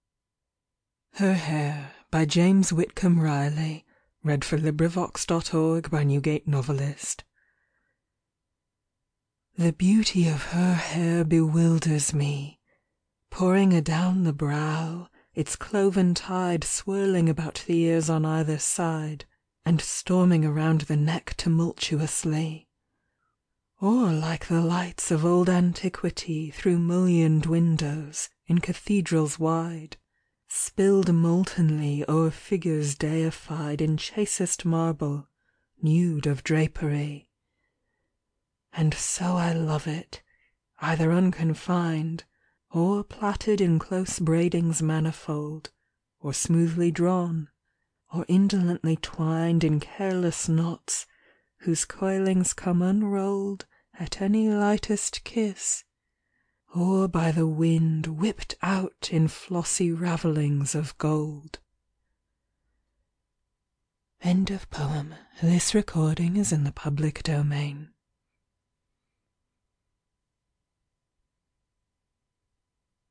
In the case of this recording, you just need to remove some interval boundaries.